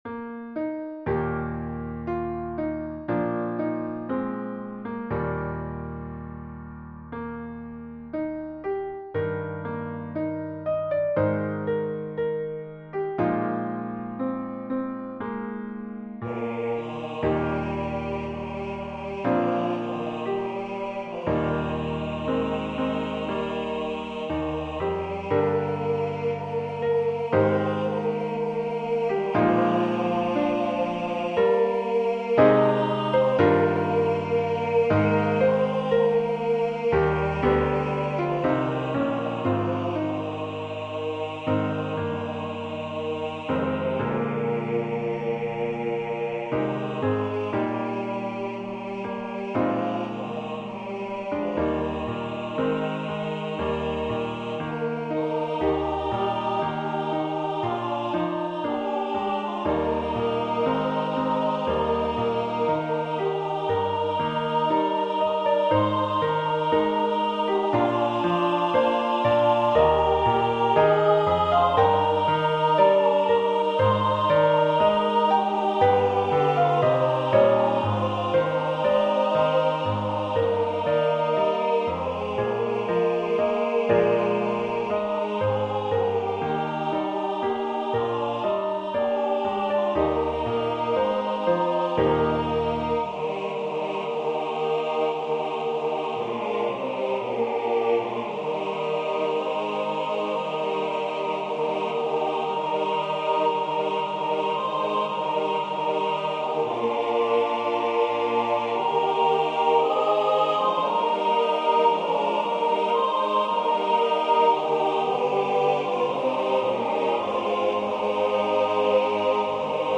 Were You There? Hymn #1206 SATB with Piano Accompaniment.
This piece is meant to be sung rubato and with soul/thoughtfulness, especially the 3rd verse (a cappella)